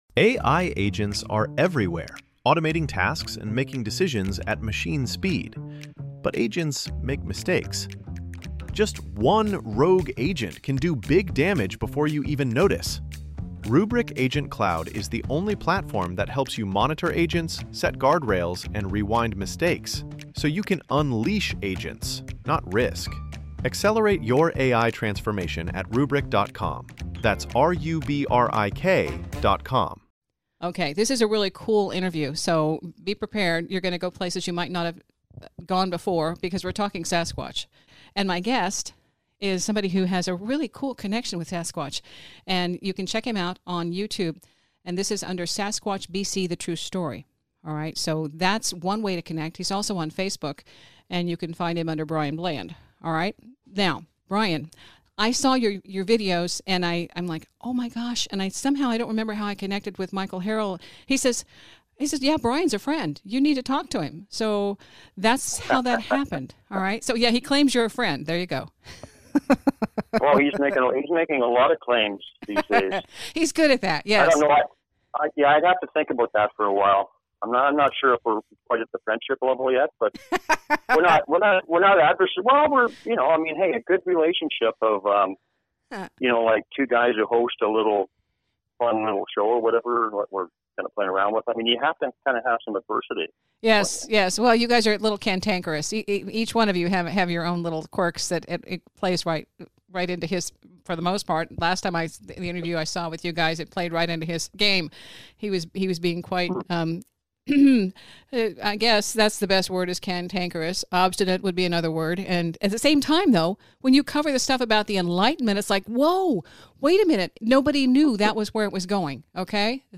This interview aired on KCMO Talk Radio 710 AM.